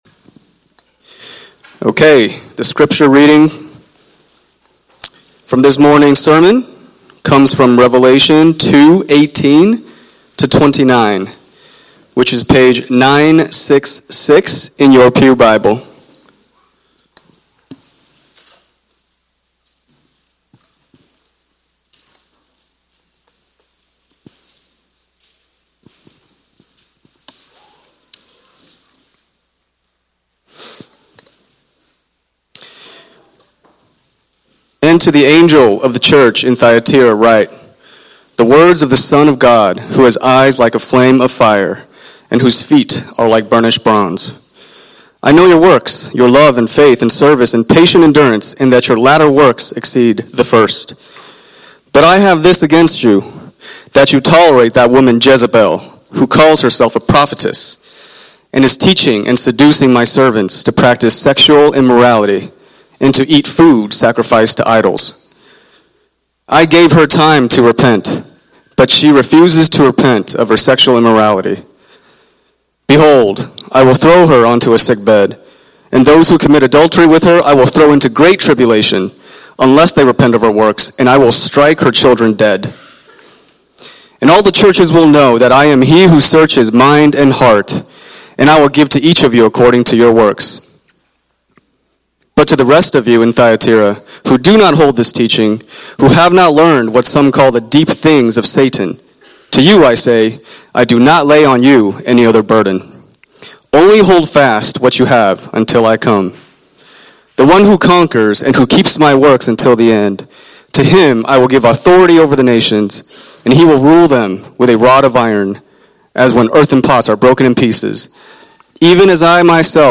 Sermons by Redemption City Church